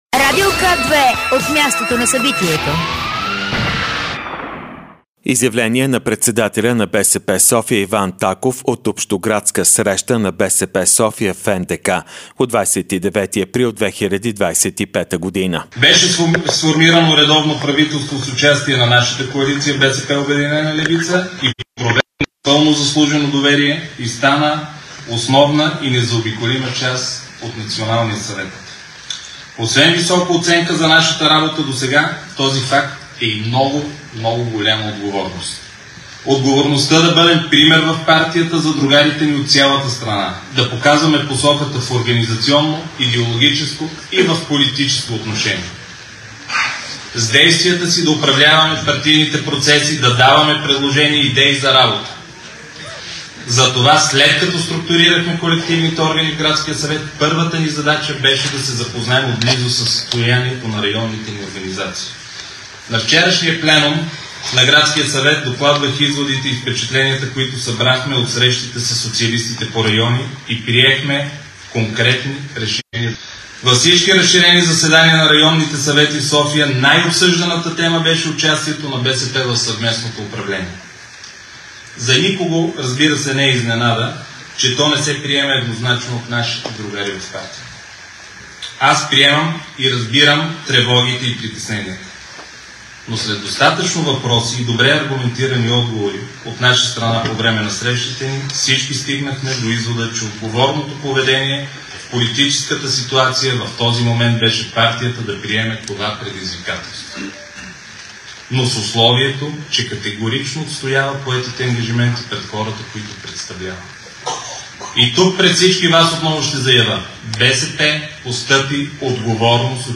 Изказване на съпредседателя на ПП Кирил Петков за първите 100 дни на кабинета Желязков, от 30.04.2025